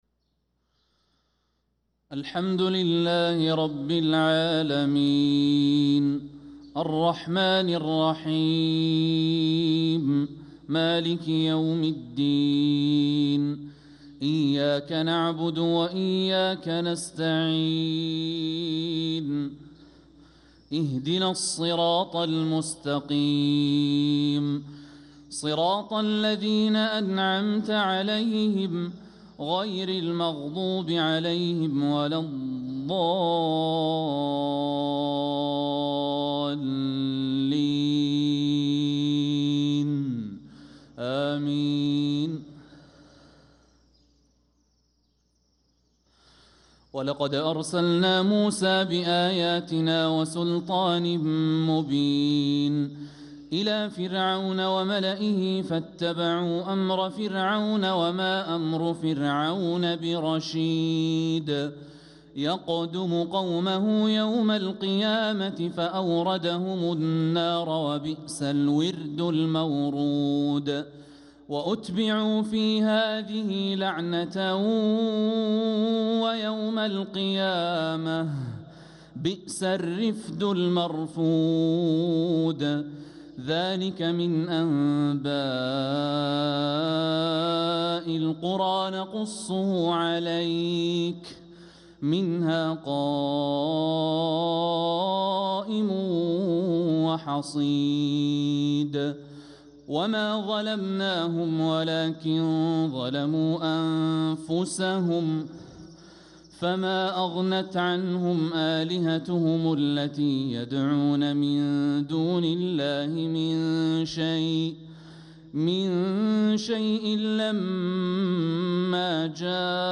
صلاة الفجر للقارئ الوليد الشمسان 21 جمادي الأول 1446 هـ
تِلَاوَات الْحَرَمَيْن .